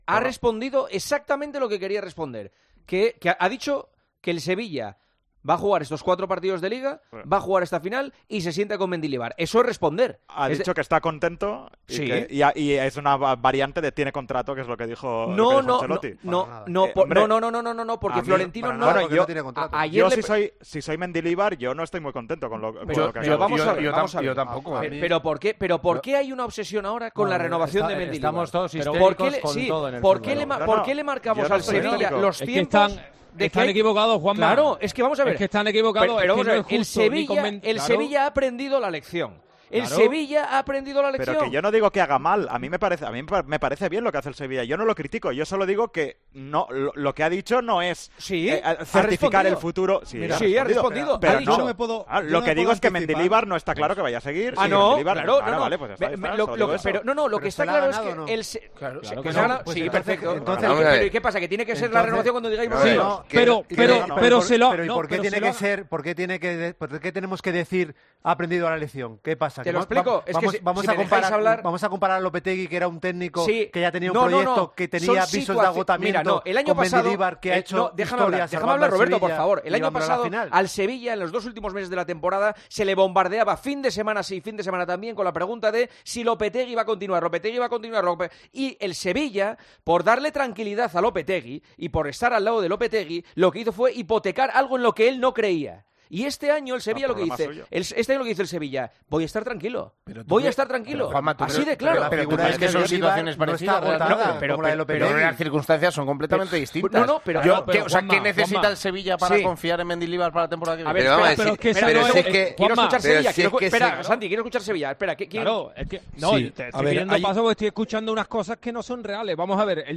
El debate en El Partidazo de COPE sobre el futuro de José Luis Mendilibar